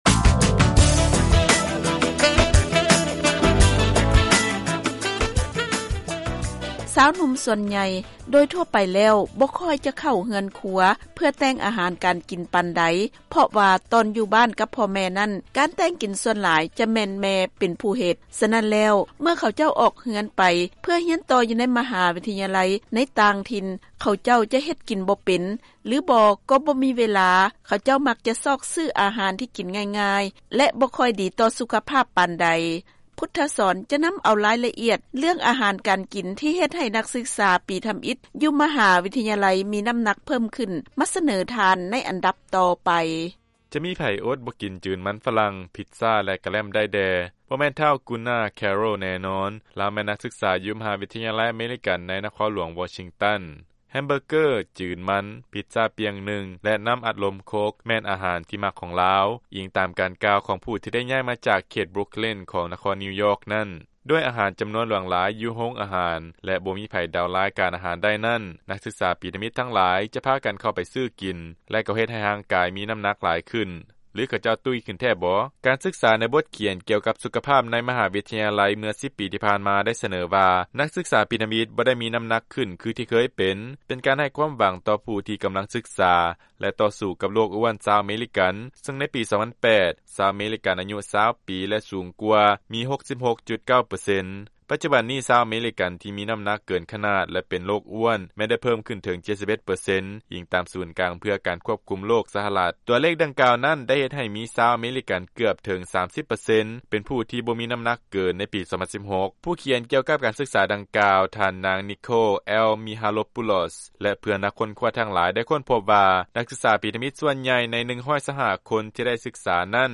ຟັງລາຍງານ ນັກສຶກສາ ມະຫາວິທະຍາໄລ ປີທຳອິດ ຈະຕຸ້ຍຂຶ້ນ 15 ປອນ ແມ່ນເລື່ອງທີ່ ຖືກປັ້ນແຕ່ງຂຶ້ນມາ